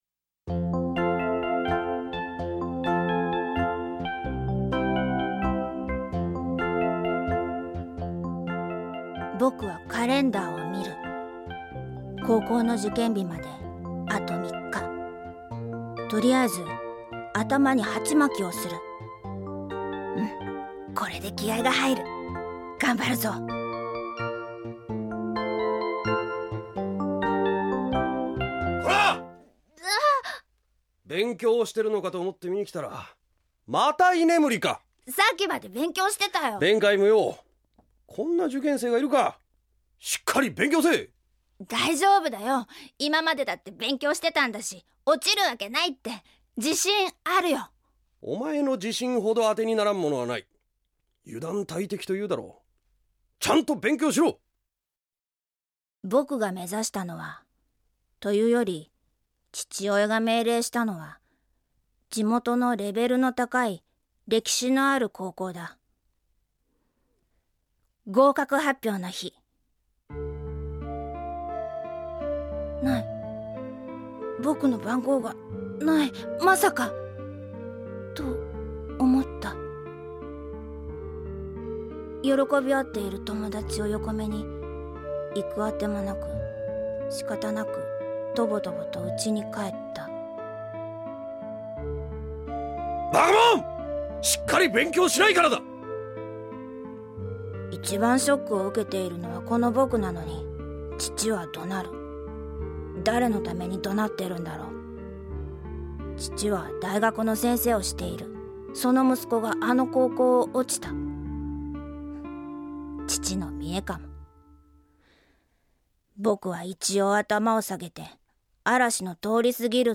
●ラジオドラマ「LIFE」
・修　（中学３年）
・教師　（女性）